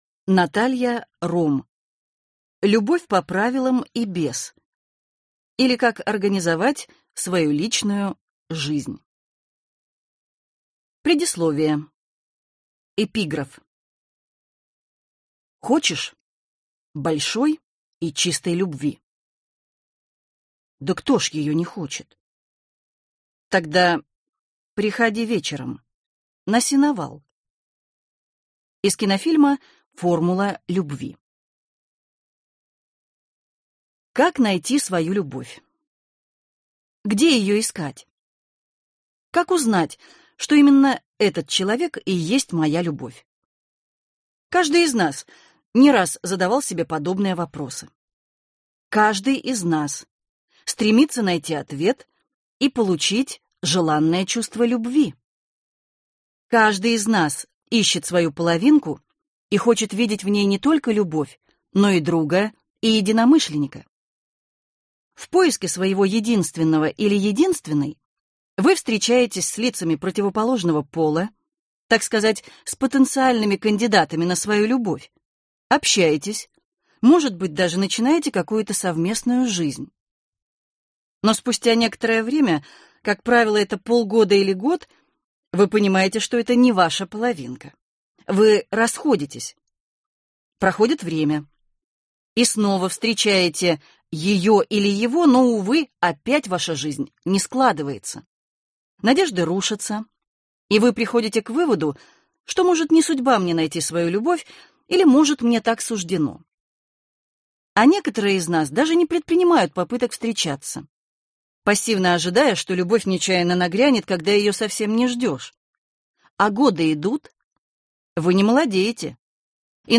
Аудиокнига Любовь по правилам и без, или Как организовать свою личную жизнь | Библиотека аудиокниг